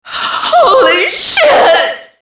Index of /cstrike/sound/quake/female